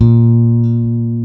-MM JAZZ A#3.wav